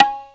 R8Tabla1.wav